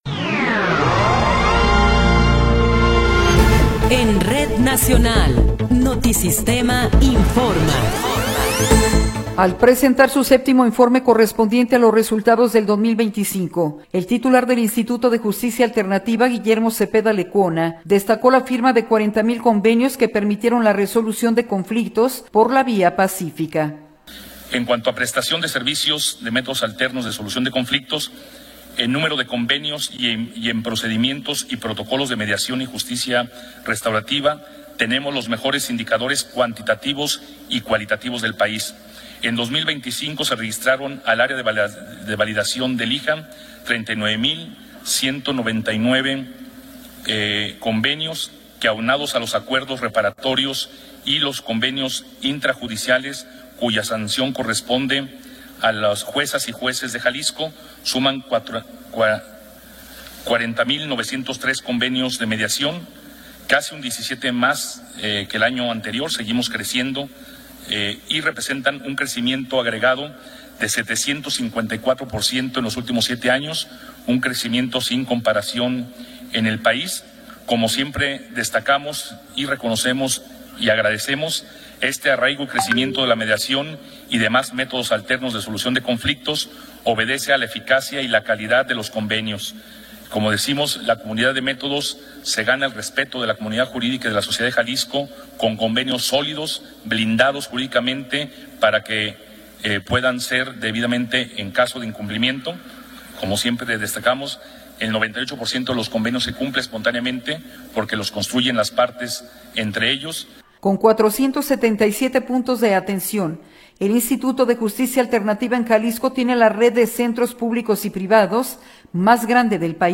Noticiero 11 hrs. – 18 de Enero de 2026